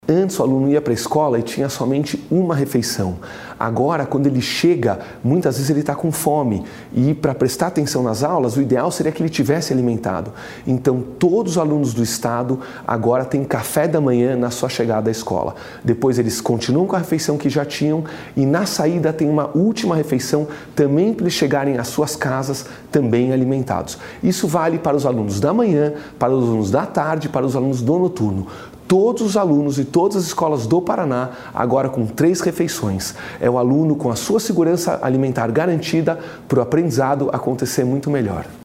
Sonora do secretário da Educação, Renato Feder, sobre o programa Mais Merenda